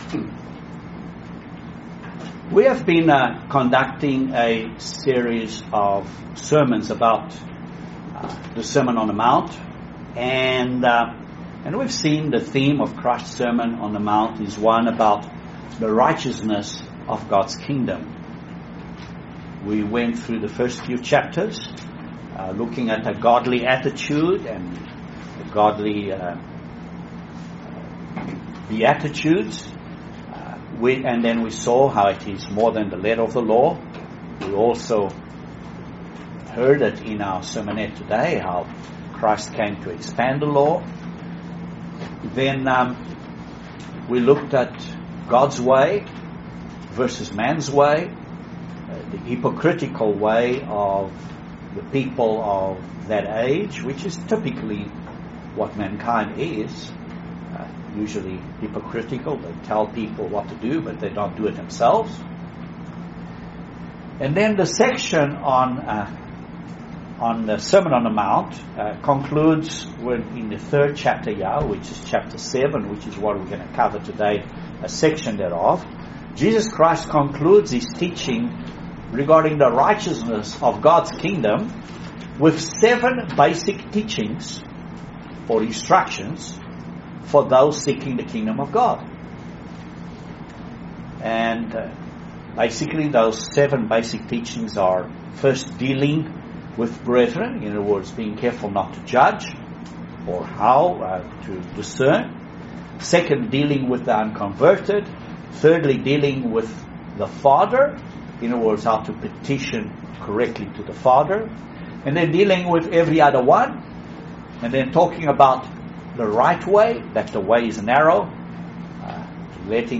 Excellent sermon on Judging others - in the Church - or the unconverted. Are you too quick to judge others?